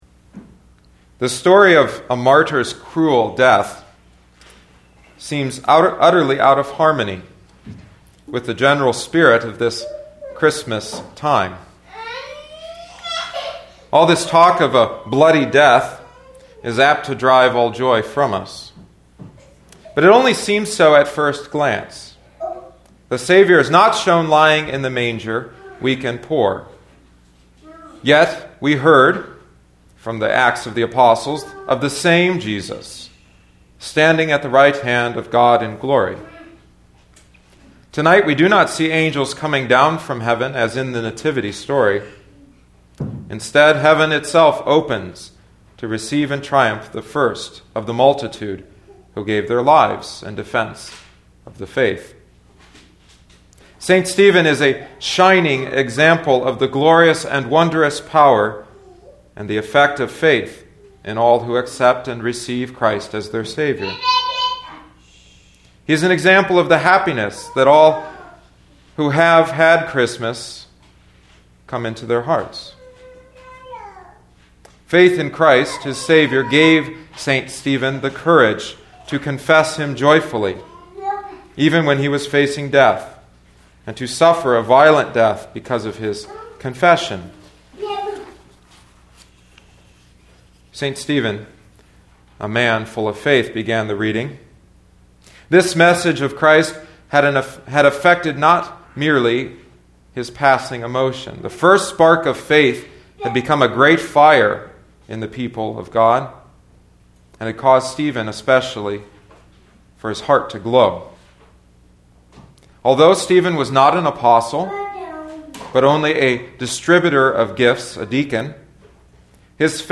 The sermon I preached was slightly revised and updated from The Sermon and the Propers by Fred H. Lindemann and published in 1958-59 by Concordia Publishing House.